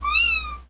Miyaaav.ogg